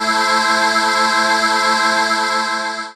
Index of /90_sSampleCDs/Techno_Trance_Essentials/CHOIR
64_18_voicesyn-A.wav